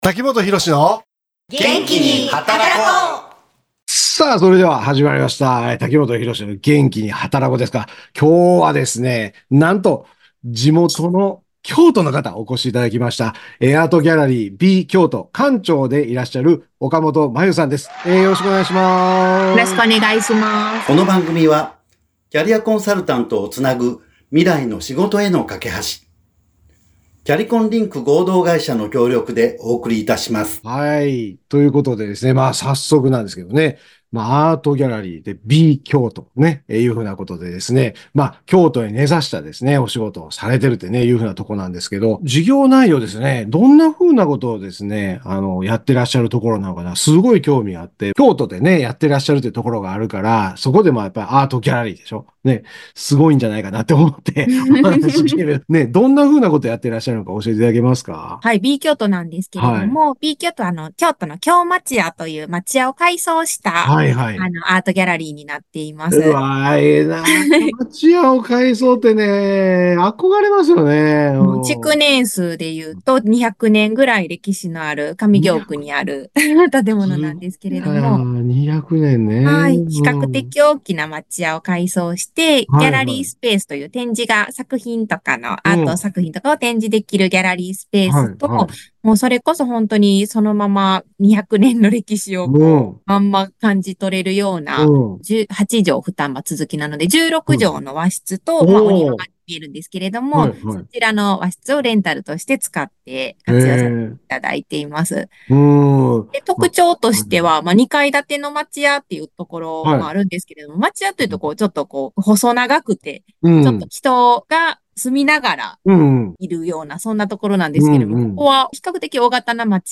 FM79.7京都三条ラジオカフェ2024年5月9日放送分です。